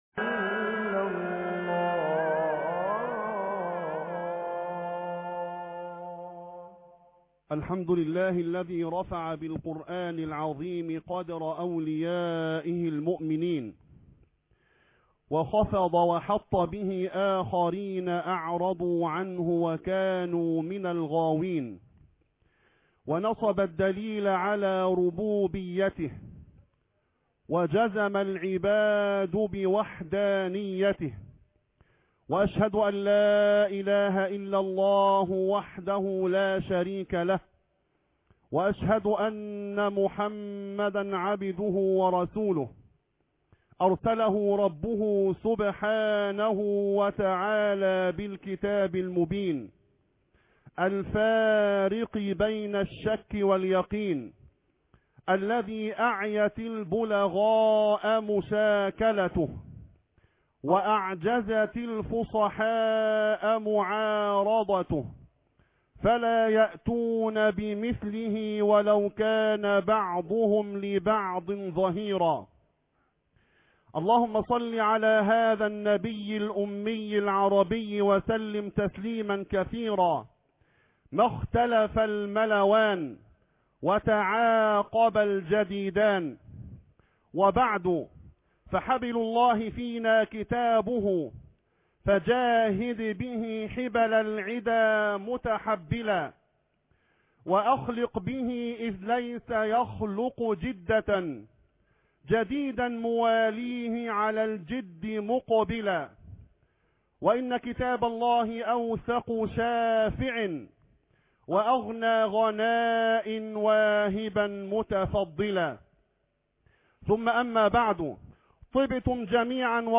حفل تكريم حفظة كتاب الله ( 13/11/2011 ) فى بيوت الله - قسم المنوعات